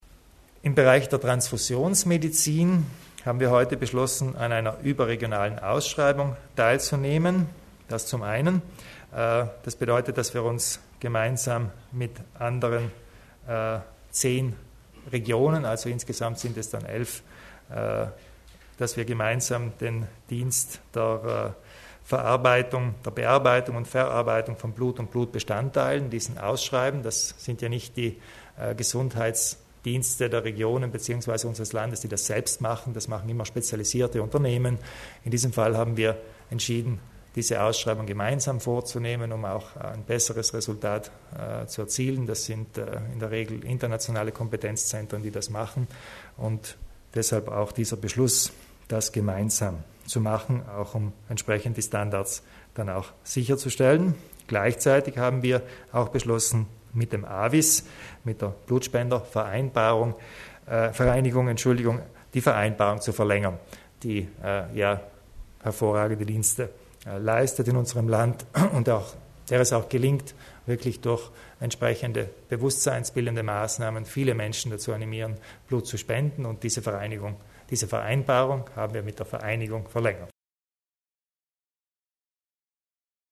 Landeshauptmann Kompatscher zur Bedeutung der interregionalen Zusammenarbeit im Gesundheitswesen